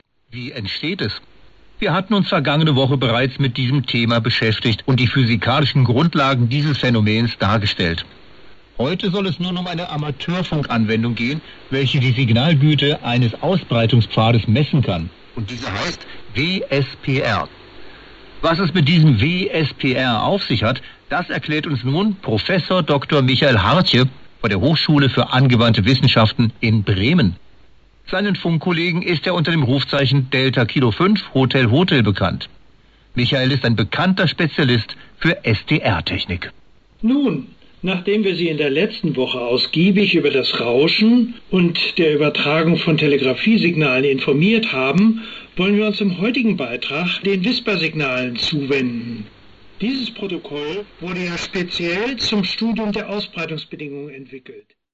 RX: LOWE HF-150
antenna: HiQBBA